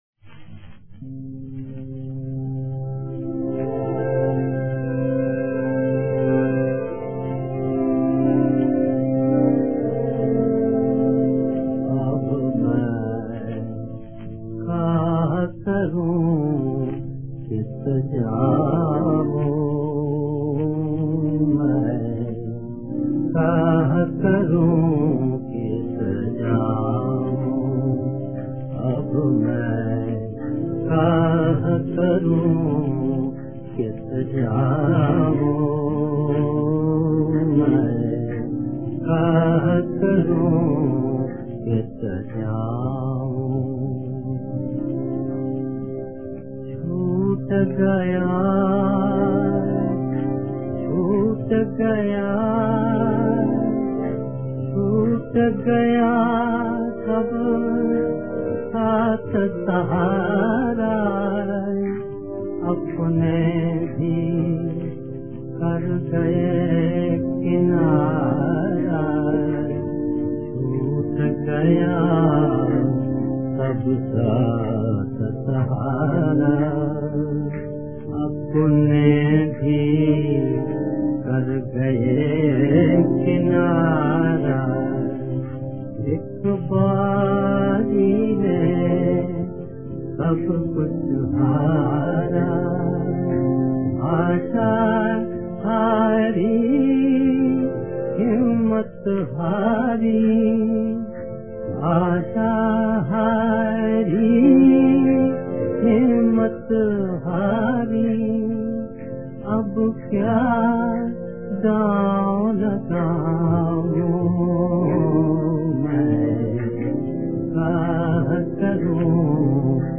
Hindi Film Song